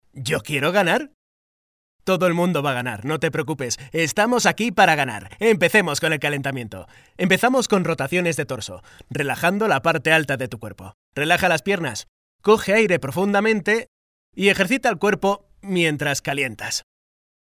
The studio features expert acoustic design and the latest in digital audio technology like : Microphones:  Kahayan 4k7 . Neumann TLM 170. Tube Tech equalizer, Apogee Symphony...  Quality and the better sound.